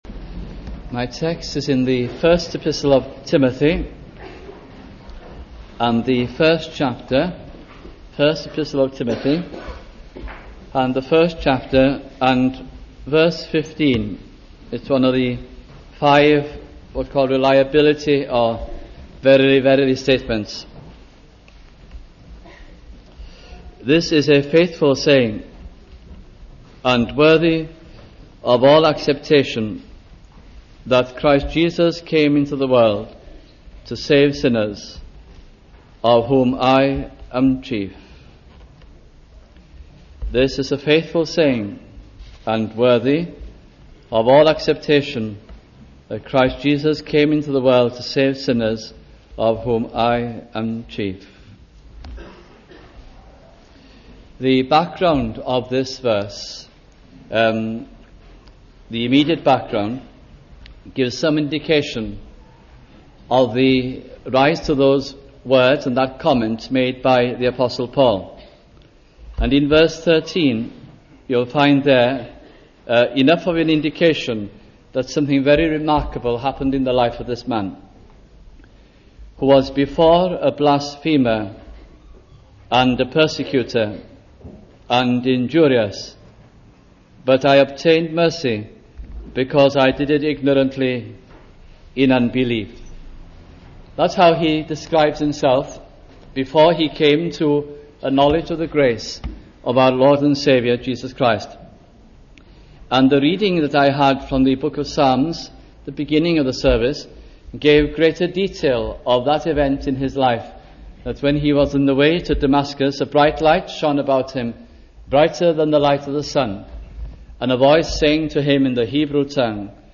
» 1 Timothy Gospel Sermons